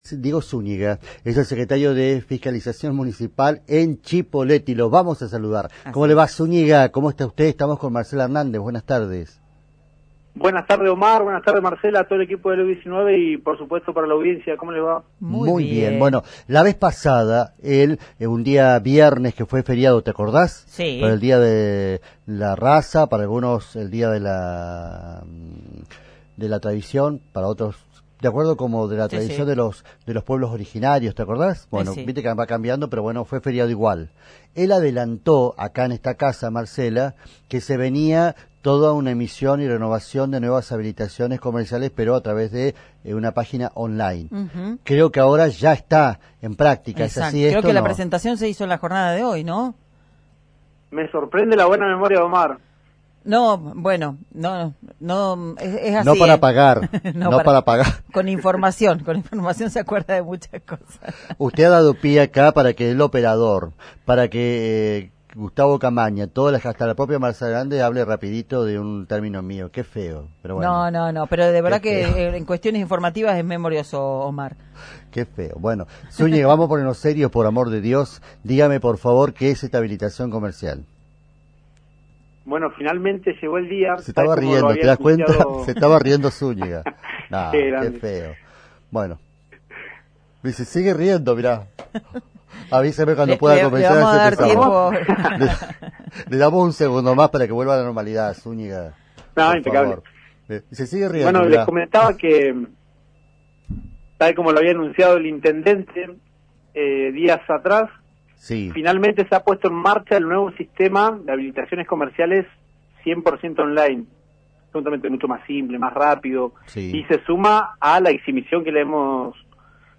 Escuchá la entrevista completa con Diego Zúñiga en el audio adjunto.